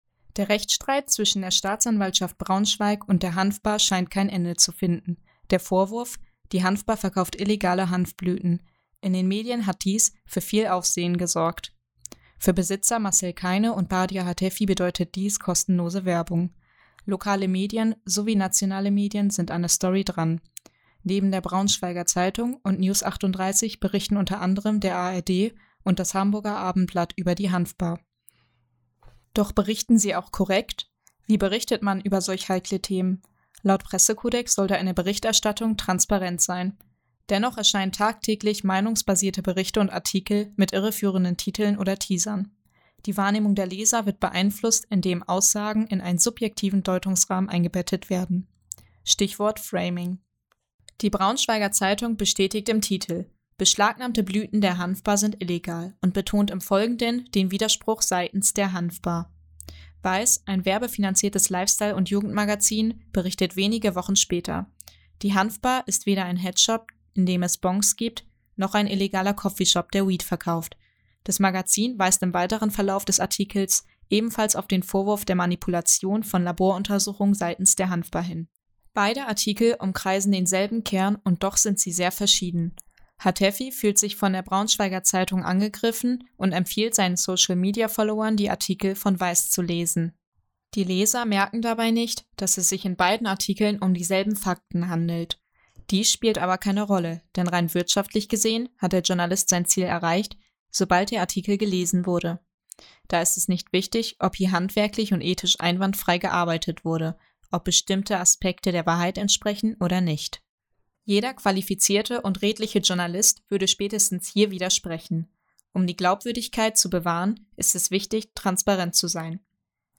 Ein Audio-Kommentar.